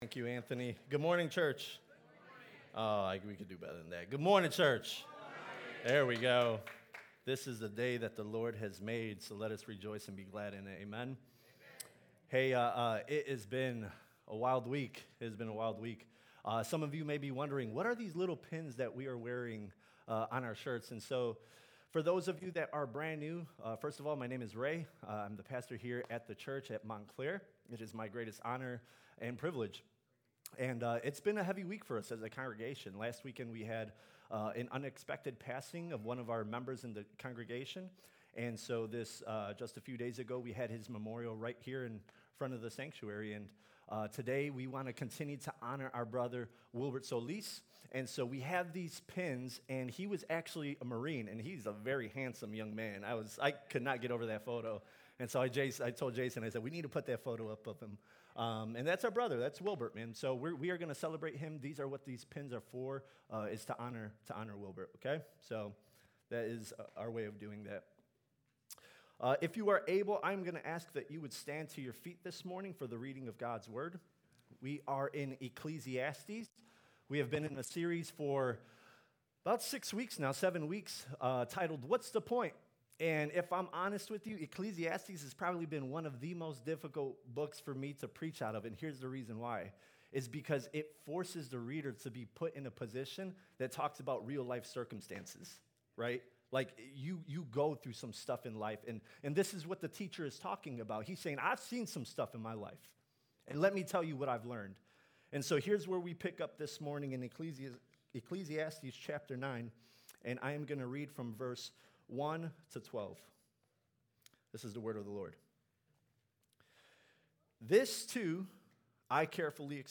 Sermons and Audio Messages from New Life Community Church